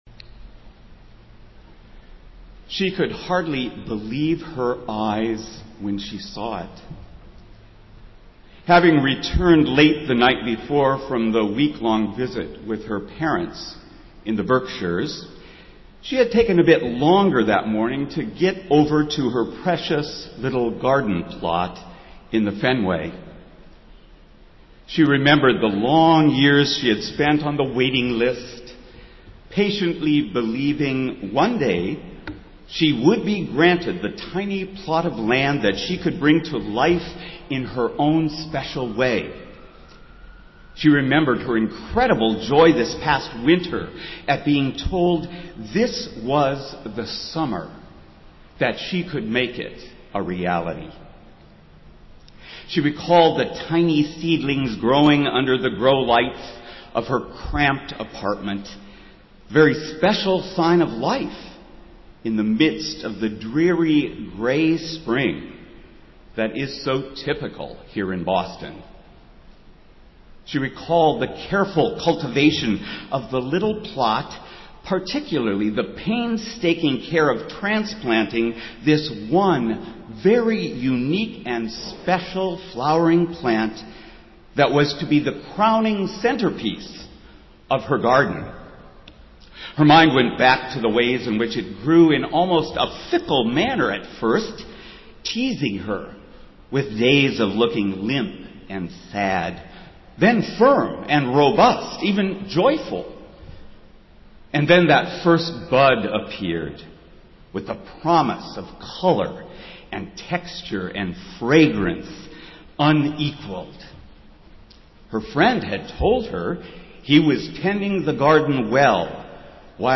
Festival Worship - Fifth Sunday after Pentecost